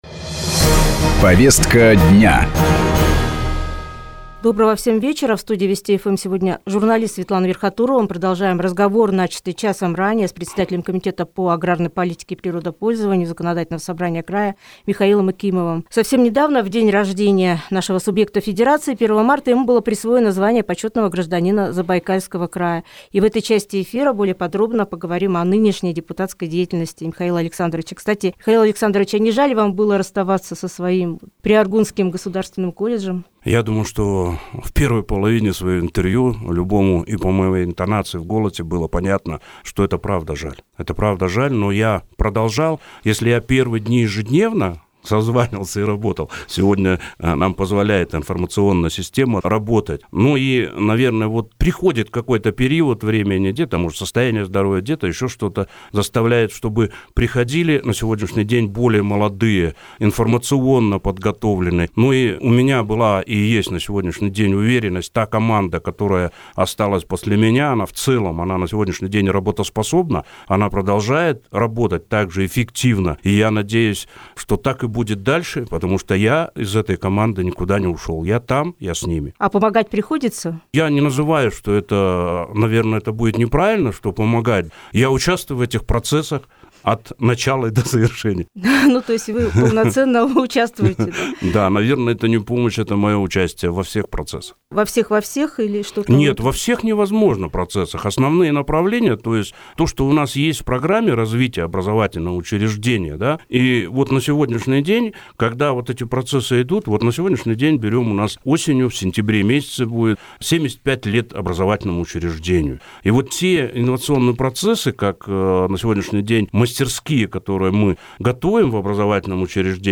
Почетный гражданин Забайкалья, депутат Михаил Якимов ответил на вопросы программы